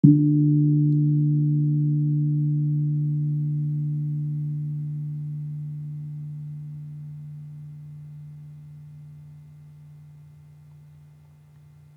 HSS-Gamelan-1 / Gong
Gong-D2-f.wav